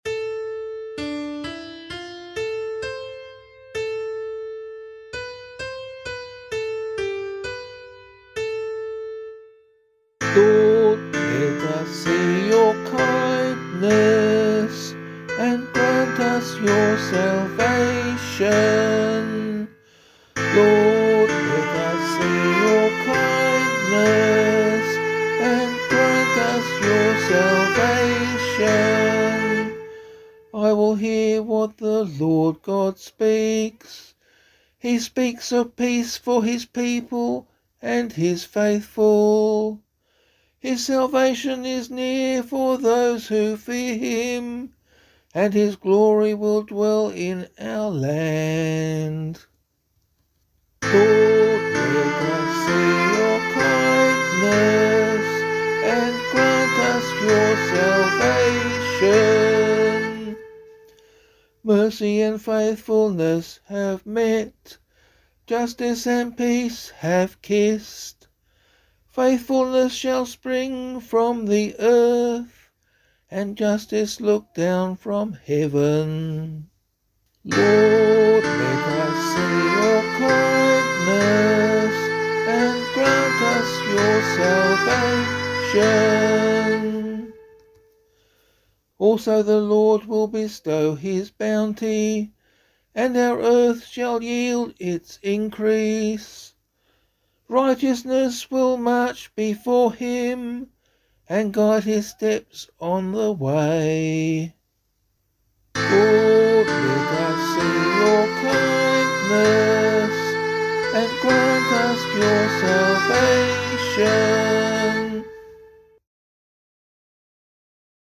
002 Advent 2 Psalm B [Abbey - LiturgyShare + Meinrad 4] - vocal.mp3